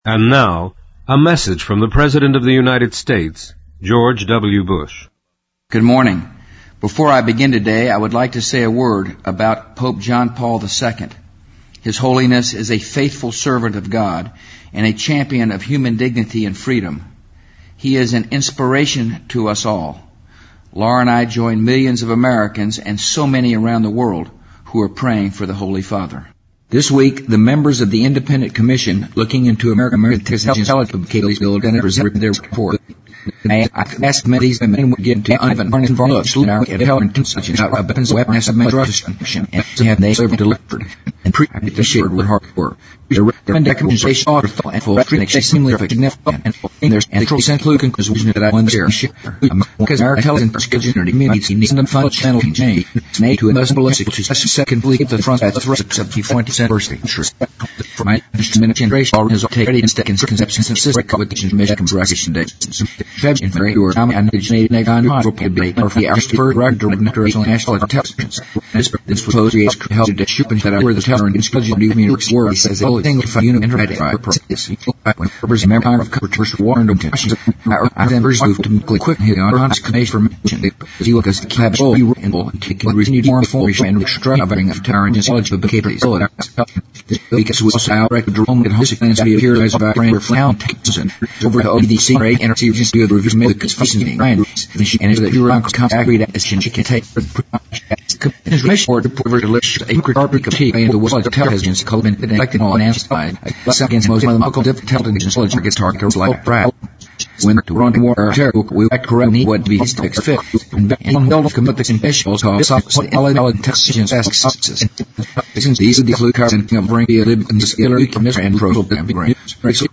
President Bush-2005-04-02电台演说 听力文件下载—在线英语听力室